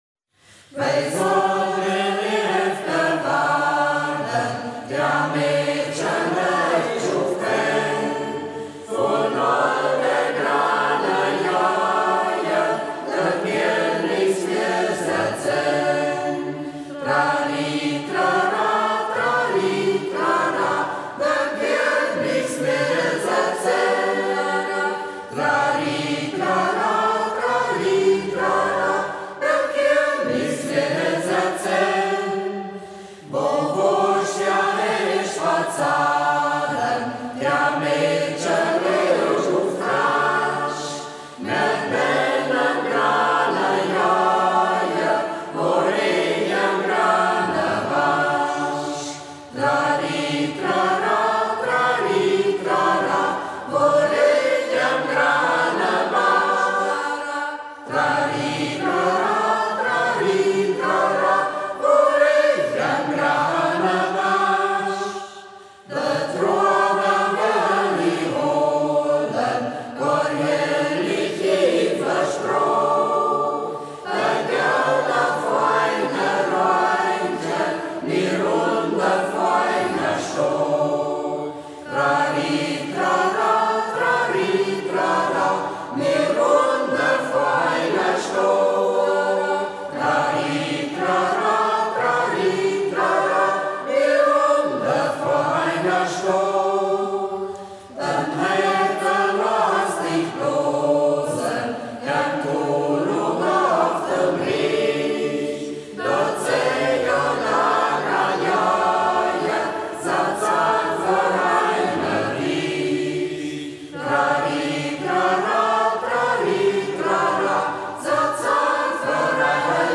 Ortsmundart: Braller